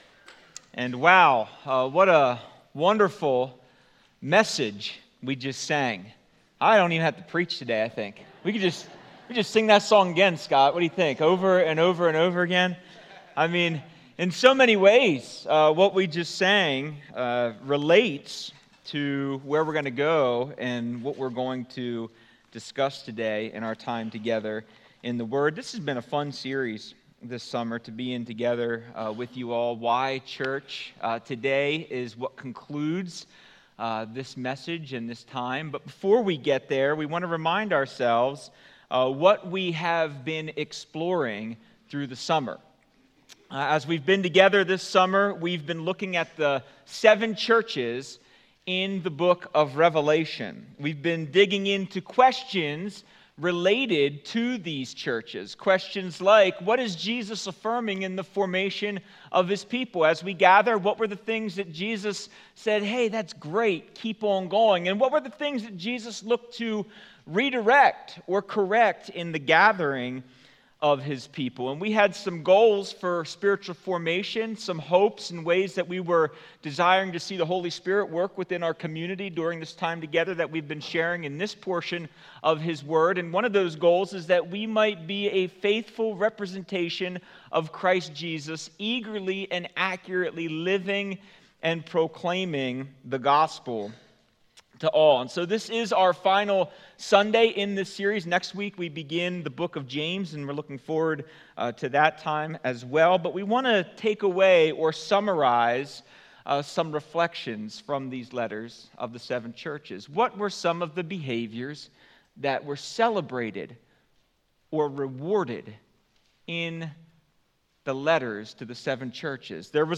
In this Together Current Sermon Why Church?